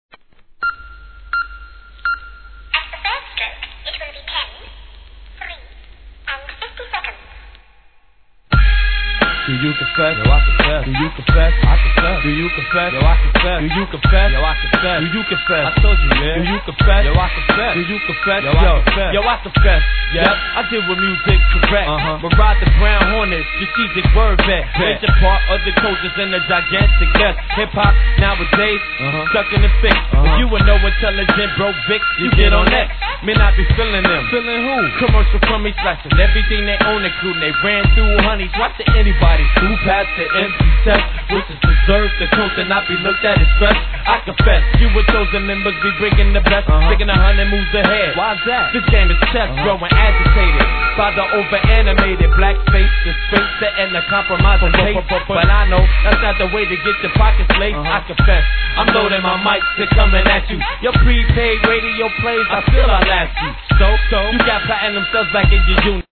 HIP HOP/R&B
落ち着いたプロダクションで、2002年のシカゴ産優良ヒップホップ作!